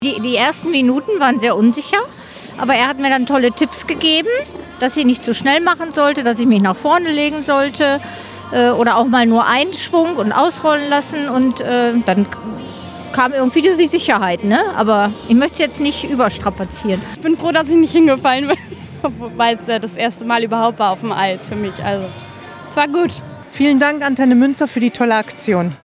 Das Aftershow-Skating - so fanden es die Gewinner